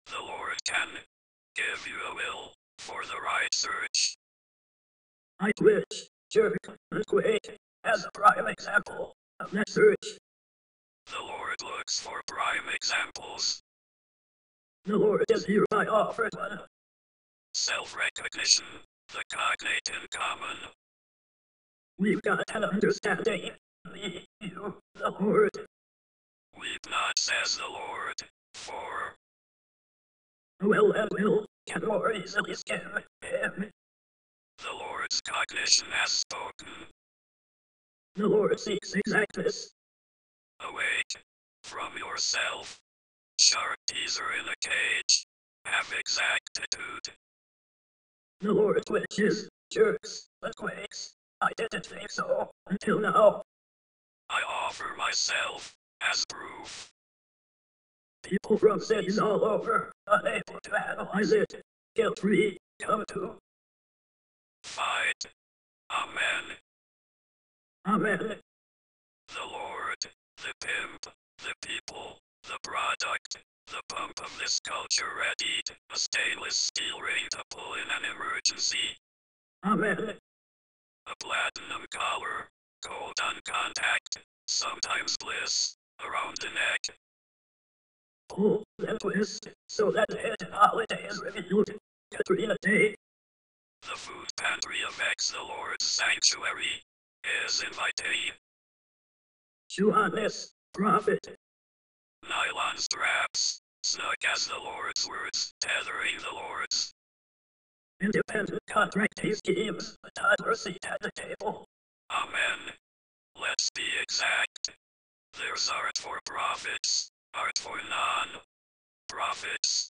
radio show